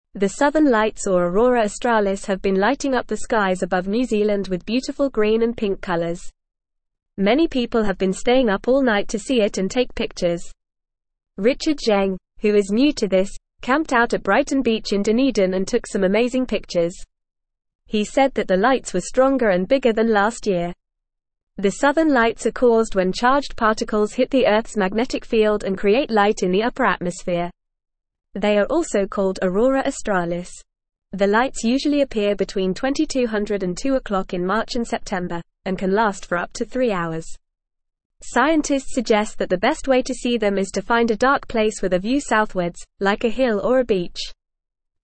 Fast
English-Newsroom-Beginner-FAST-Reading-Pretty-Colors-in-the-Sky-at-Night.mp3